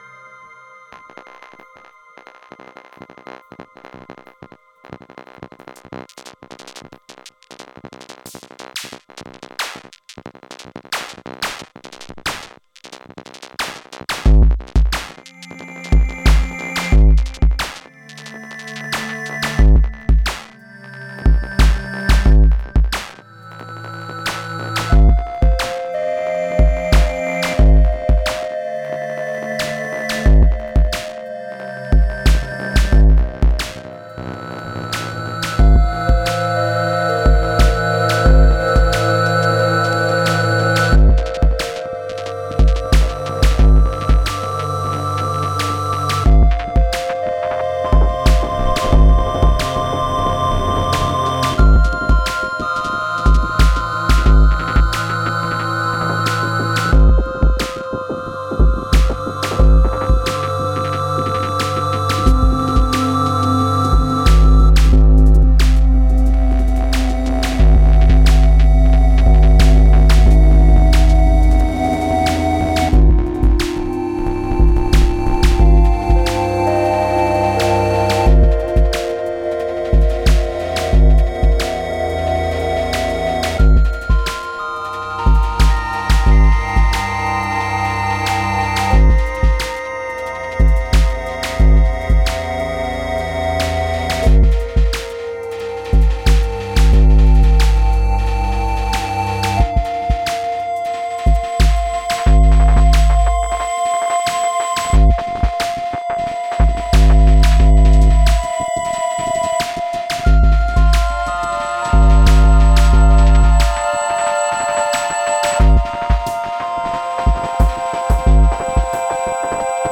Some random shit. Sloppy but the promise is there for how I want to work.